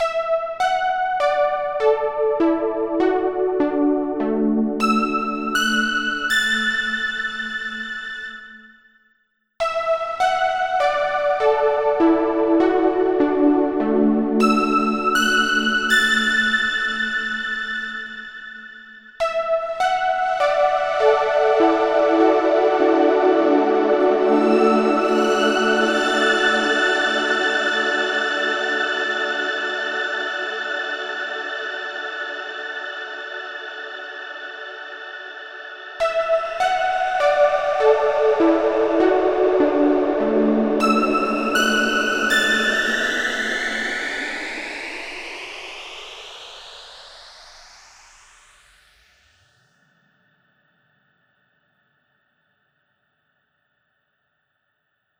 Короче запилилась сразу же коротенькая демка. 4 части: 1. Сухой исходник 2. Пресет по умолчанию, алгоритм Airy 3. Алгоритм Cosmic с автоматизацией Dry/Wet от 25% до 100% 4. Алгоритм Grounded с автоматизацией Size от 100% до 0% Вложения RAUM.mp3 RAUM.mp3 2,1 MB · Просмотры: 2.990